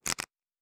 Cards Shuffle 2_05.wav